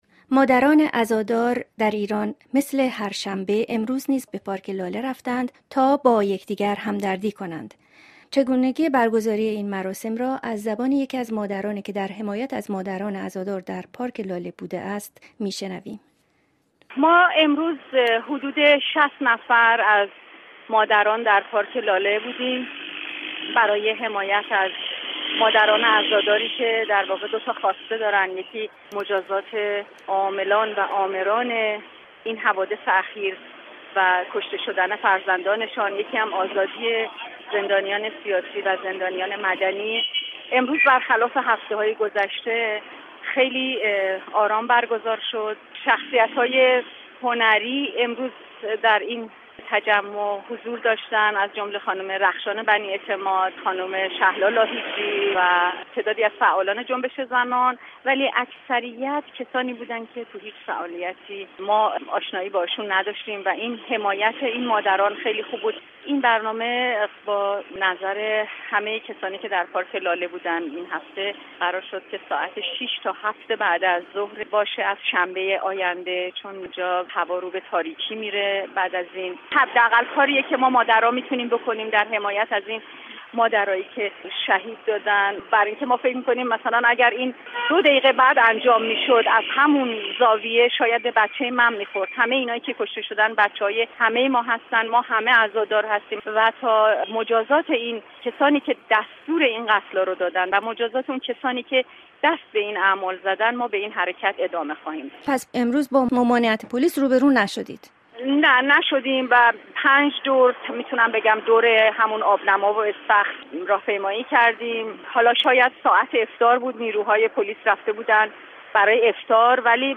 یکی از شرکت کنندگان در مراسم پارک لاله